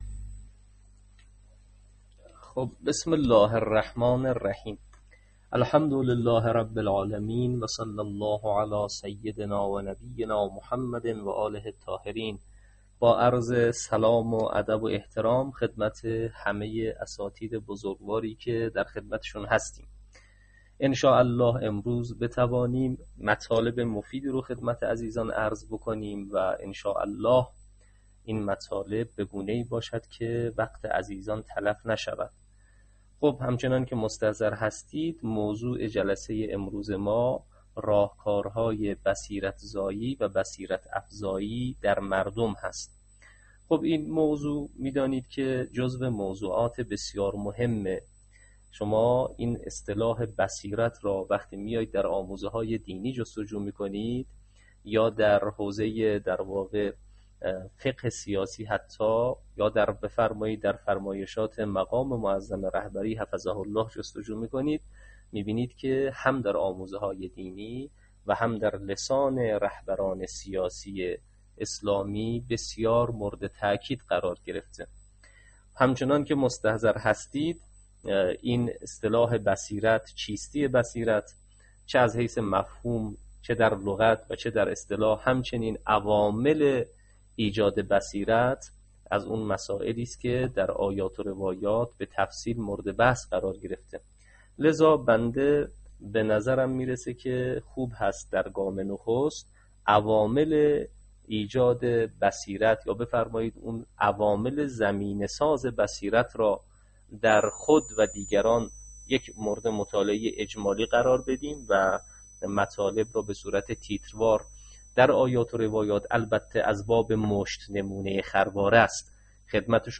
نشست علمی راهکارهای بصیرت زایی و بصیرت افزایی در مردم در جمع برخی از اساتید دانشگاههای پیام نور.mp3